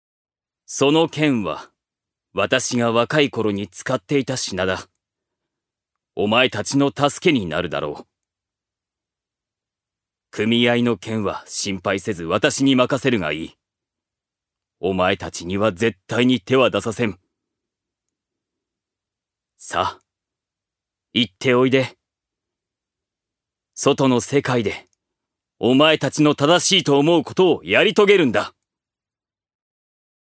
預かり：男性
セリフ４